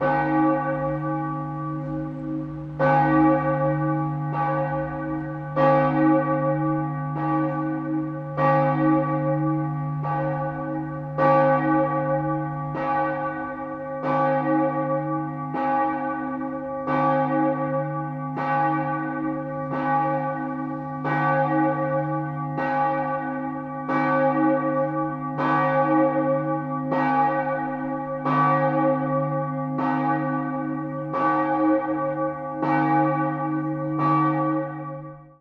Звуки колокола
Вариант 2 (звук удара в колокол)